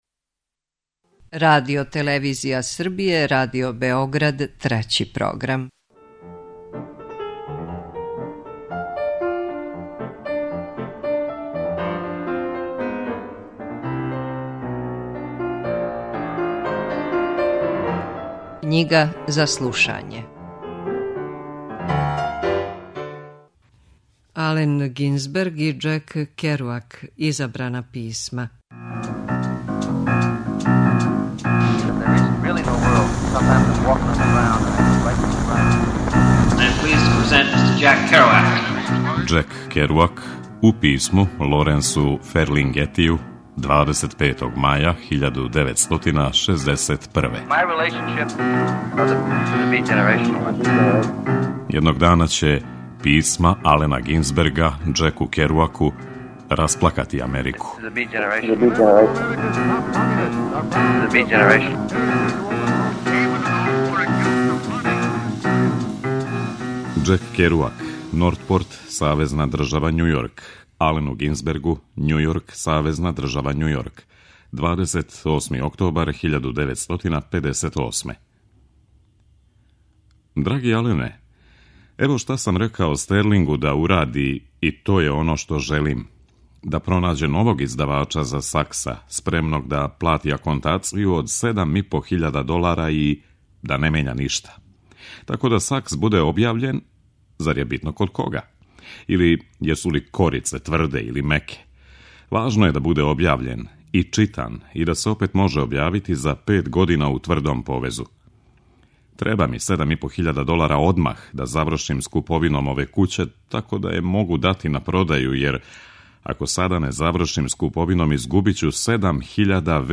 Књига за слушање: Ален Гинзберг и Џек Керуак: Изабрана писма (26)